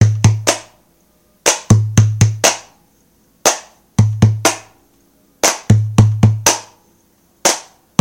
敬请关注 " FDA打击乐
描述：用于个人和商业音乐制作的样本/循环的集合。
标签： 声音 摇滚 民俗 吹口哨 鼓的节拍 人声的循环 原创音乐 吉他 自由 节拍 低音 声学吉他 钢琴 合成器 采样 和谐 创意公地 打击乐 独立民谣 清唱 旋律 声音 循环 循环 独立
声道立体声